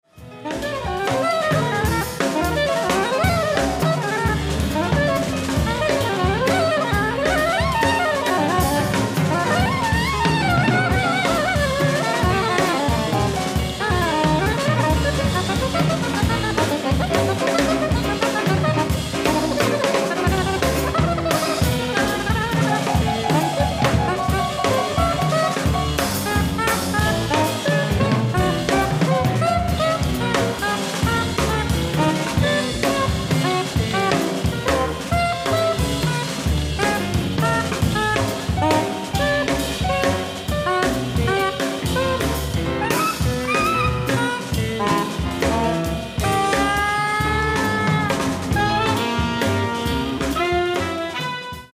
ディスク１＆２：ライブ・アット・イリンゲン、ドイツ 06/17/2009
※試聴用に実際より音質を落としています。